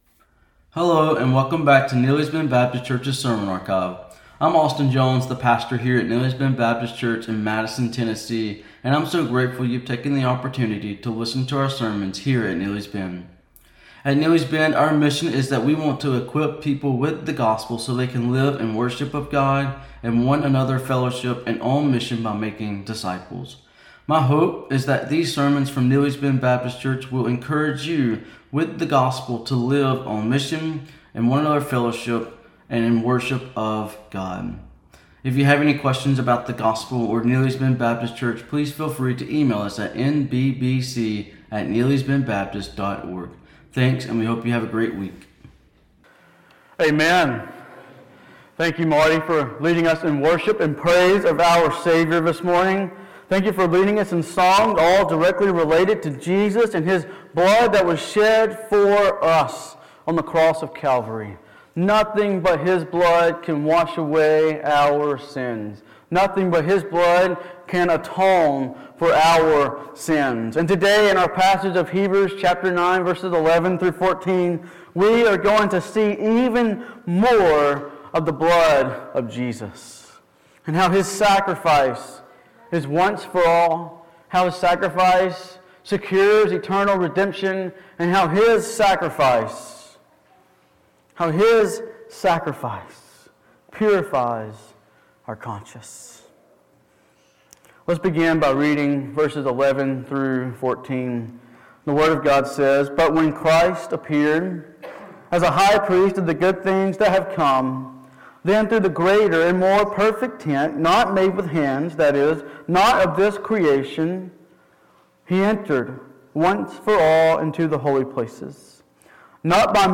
Drastically different than any sacrifice that was given under the Old Covenant and that makes Jesus' sacrifice better. In this sermon from Hebrews 9:11-14 the author demonstrates three ways Jesus' sacrifice is better.